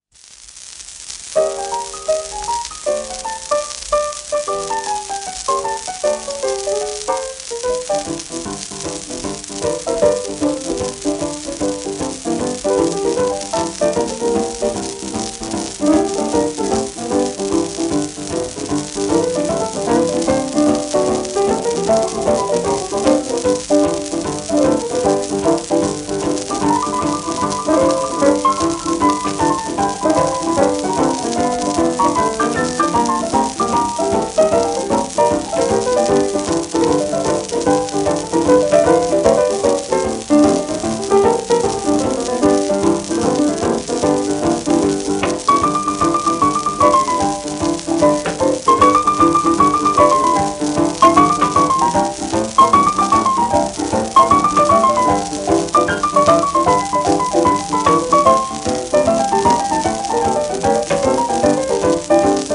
1941年頃の録音
ブギウギピアノの大家２人による共演盤。